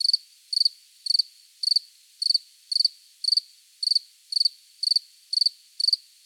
insectnight_9.ogg